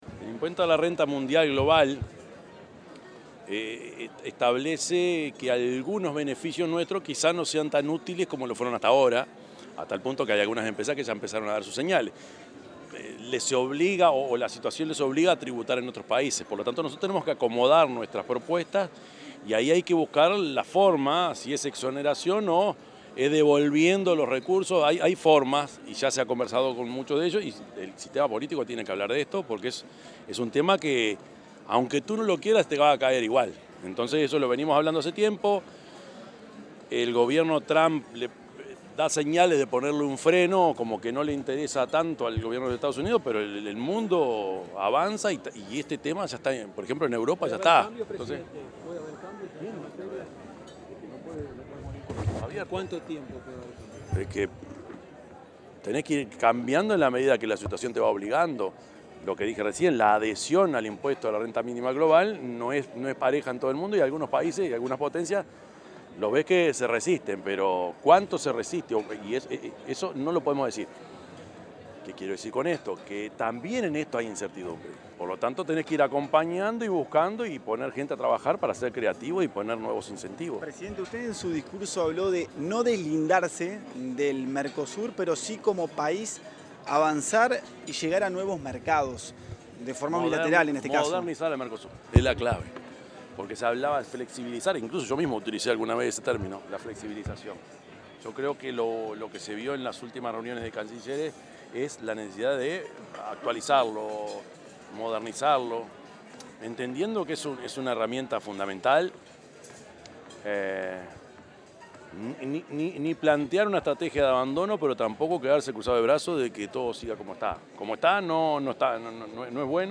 Declaraciones a la prensa del presidente Yamandú Orsi
El presidente de la República, Yamandú Orsi, dialogó con la prensa tras participar en la ceremonia conmemorativa del Día de la Exportación.